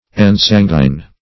ensanguine - definition of ensanguine - synonyms, pronunciation, spelling from Free Dictionary